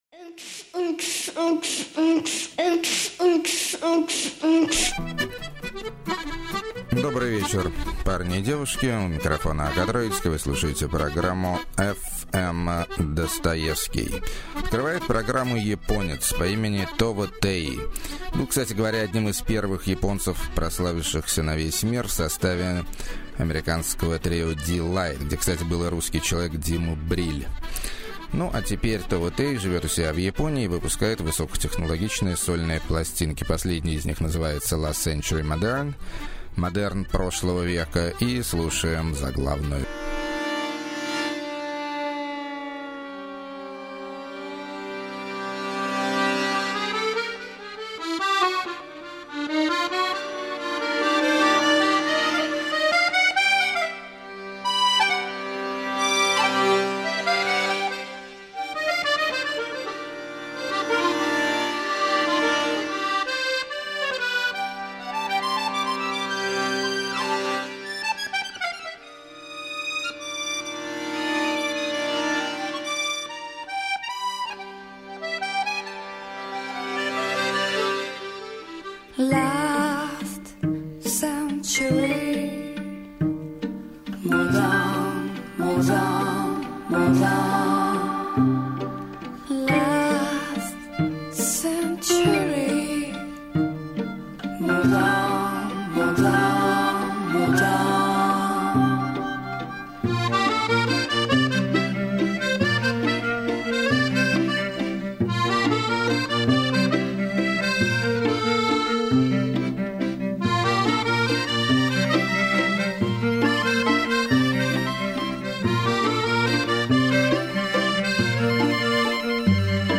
Класснейший Surf И Отмороженный Рокабилли.
Фламенко-танго-блюз.
Шизоидная Наивная Психоделия.] 12.